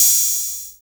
CYBER OHH.wav